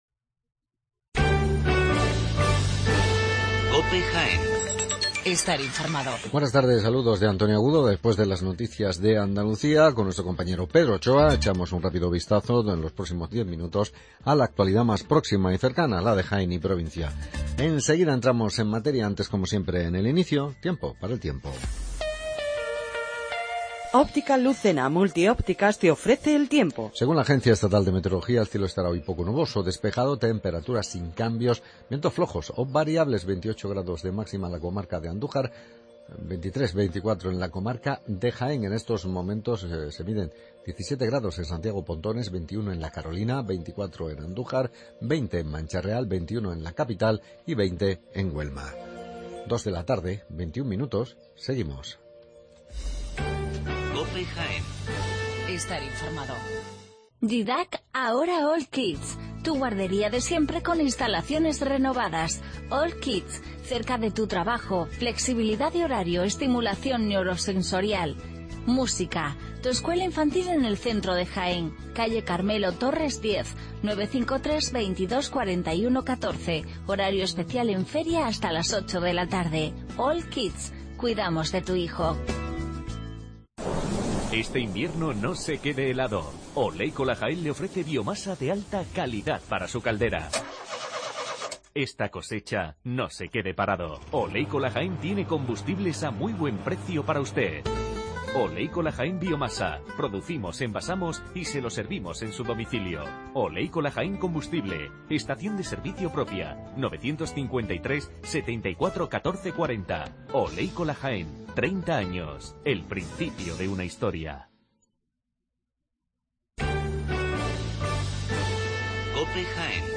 Informativo con toda la actualidad de Jaén